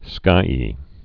(skīē)